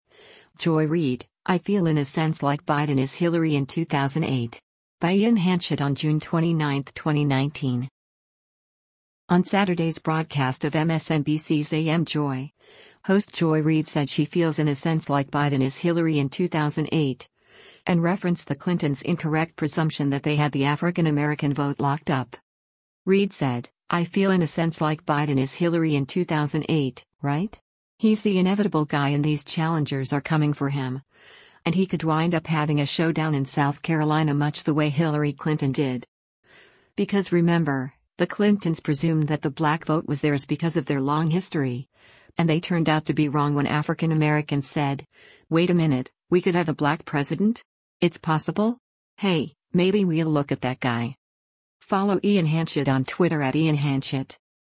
On Saturday’s broadcast of MSNBC’s “AM Joy,” host Joy Reid said she feels “in a sense like Biden is Hillary in 2008,” and referenced the Clintons’ incorrect presumption that they had the African-American vote locked up.